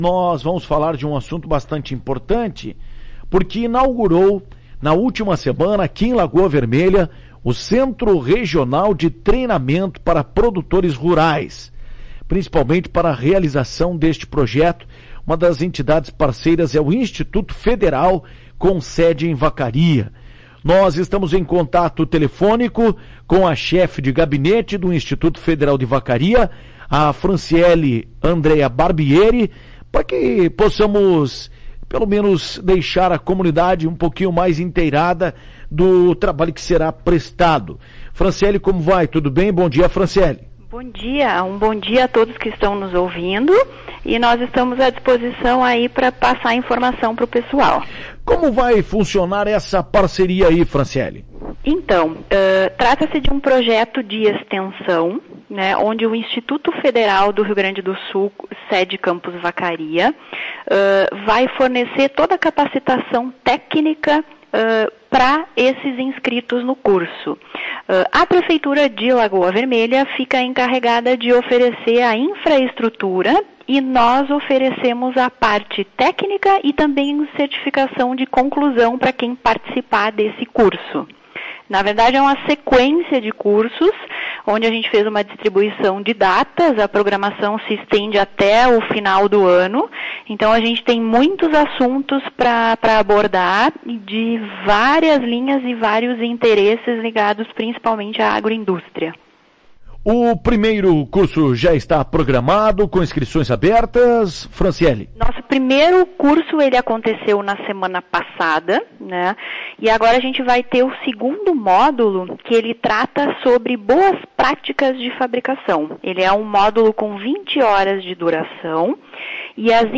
Em entrevista à Tua Rádio Cacique